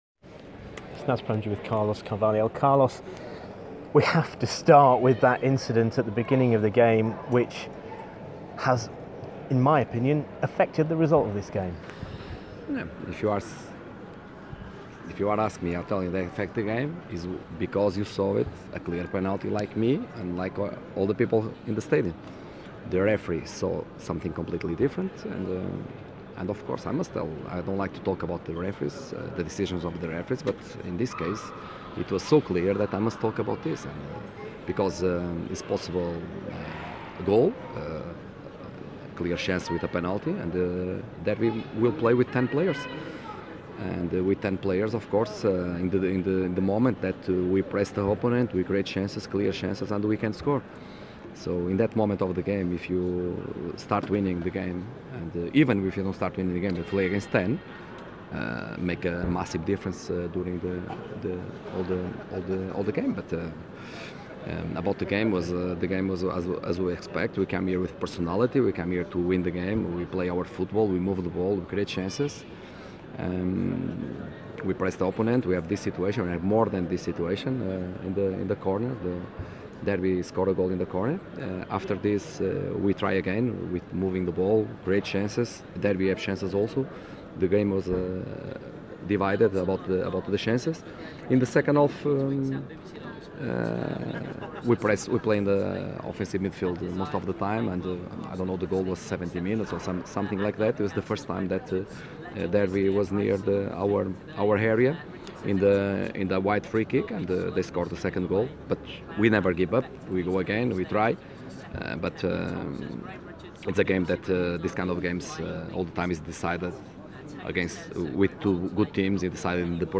Owls boss spoke of frustration at not being awarded a penalty when he was interviewed